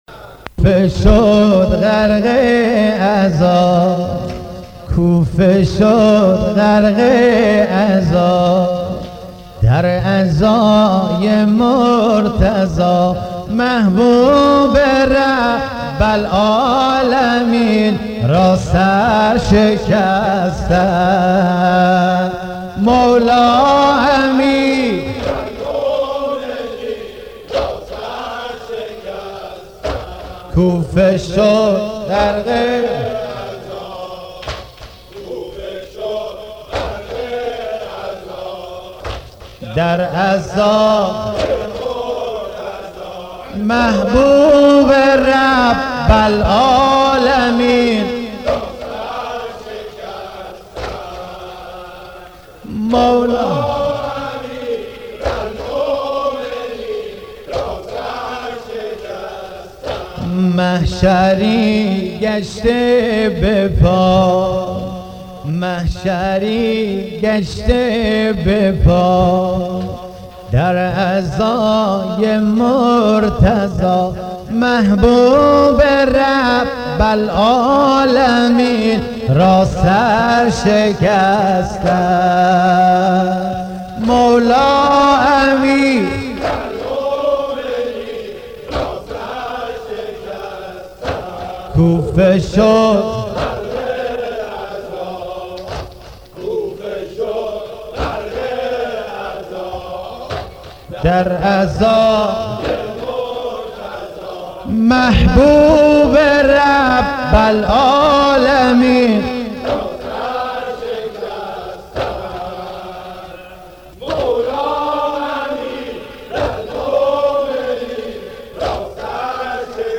عزاداری امام علی